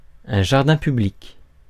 Ääntäminen
US : IPA : [pɑɹk]